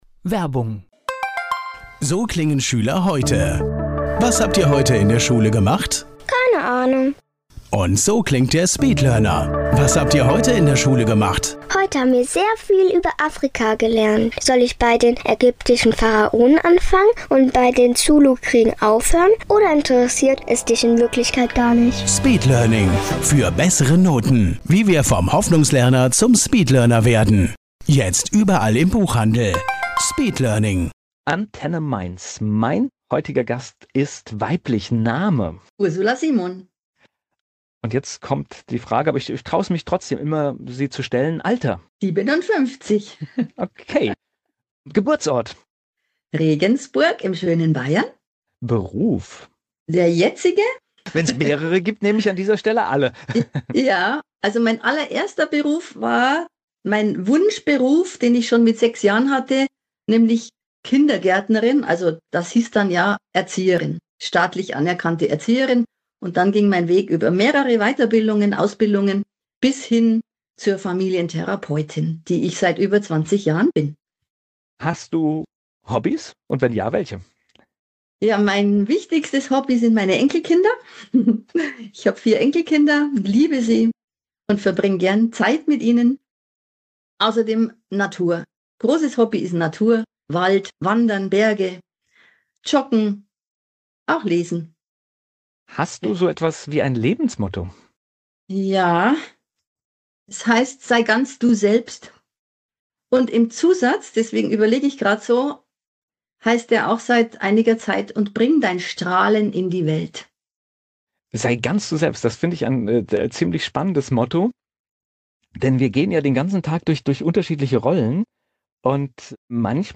1 Sonntagstalk mit Katja Adler – Ihr Weg in die Politik, Freiheit und die DDR-Vergangenheit 54:06